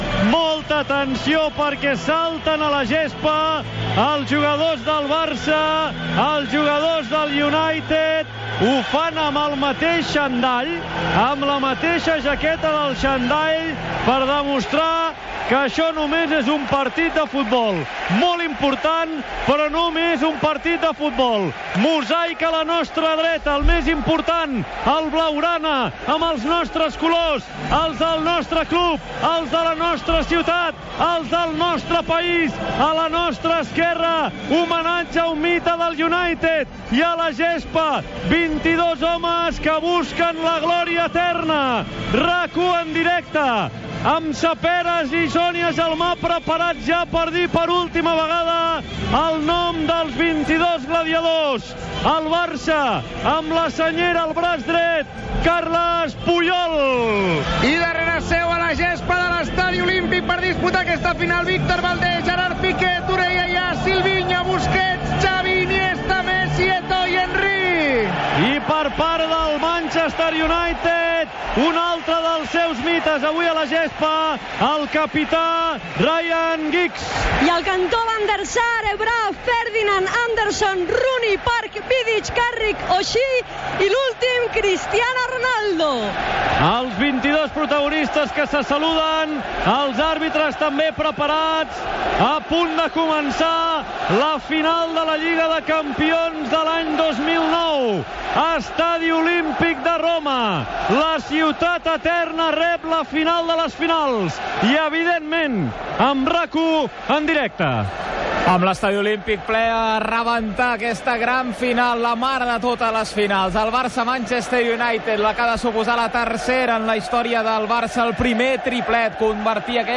Transmissió de la final de la Lliga masculina de Campions de la UEFA de 2009, des de l'estadi Olímpic de Roma. Descripció de la sortida dels jugadors, alineacions, ambient, narració de les primeres jugades i anàlisi tècnica d'algunes.
Esportiu